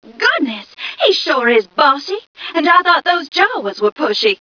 mission_voice_m2ca003.wav